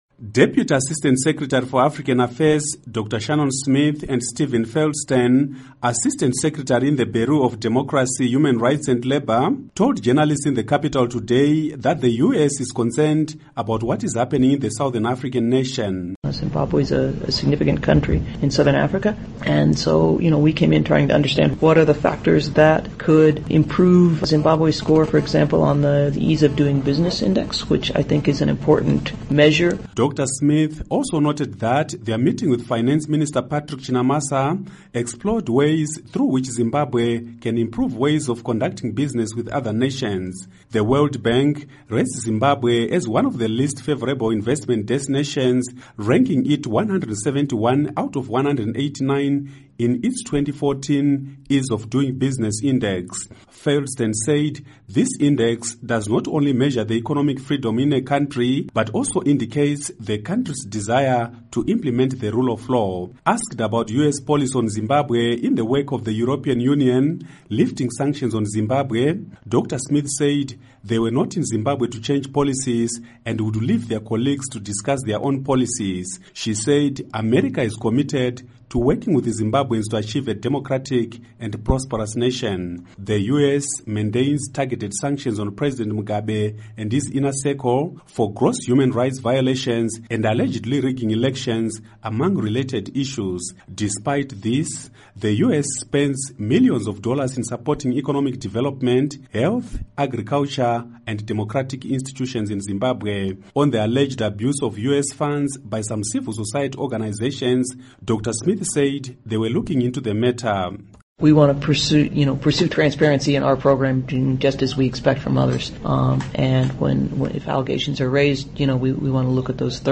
Report on U.S Envoys Visit